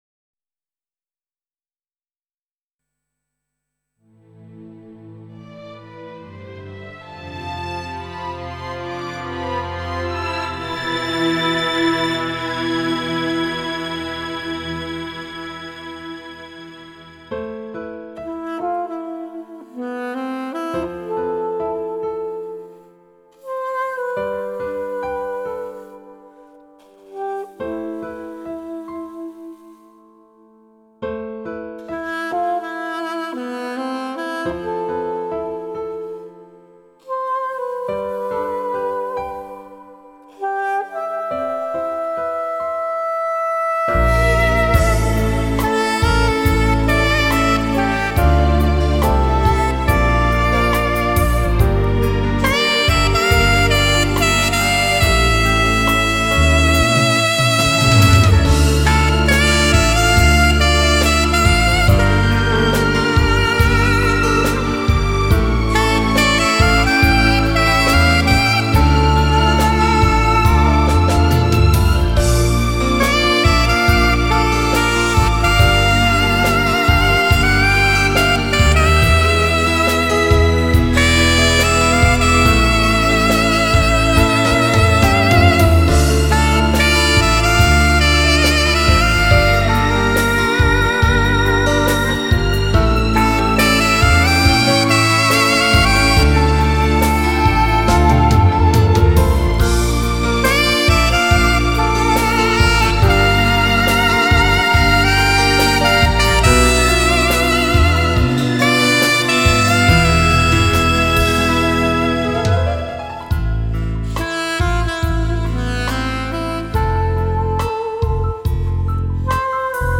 专辑歌手：纯音乐
时尚餐厅背景金曲 增添你我生活情趣
舒缓浪漫的萨克斯 脍炙人口的流行金曲
音乐细腻动人 重温浪漫情怀
沏上一杯浓香的咖啡，耳边飘起怀旧与感性的萨克斯。